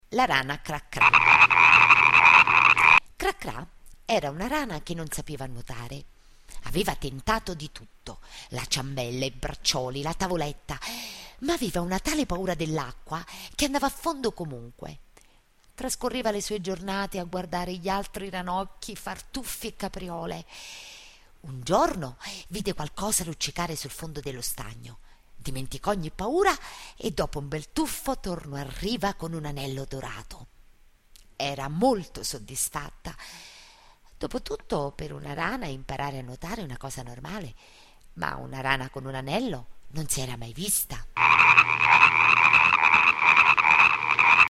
cra-cra.mp3